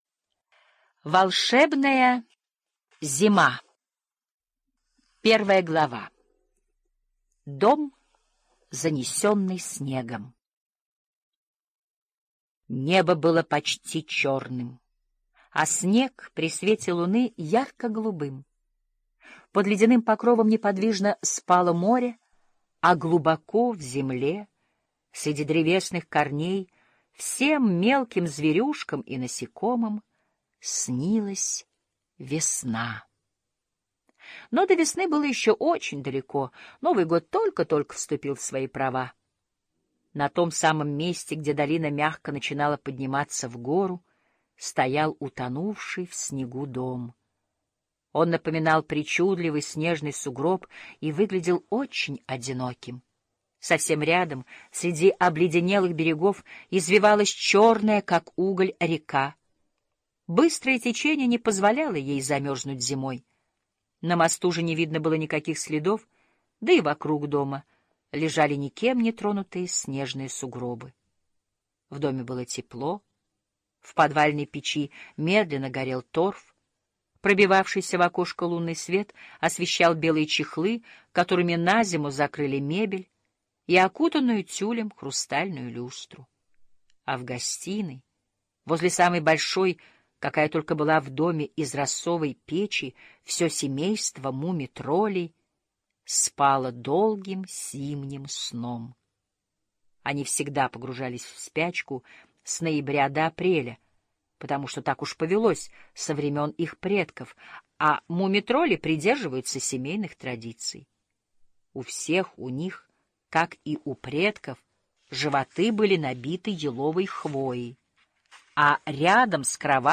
Муми-тролль и волшебная зима - аудиосказка Туве Янссон. Муми-тролли зимой спят, но один муми-тролль проснулся посреди зимы и не мог уснуть.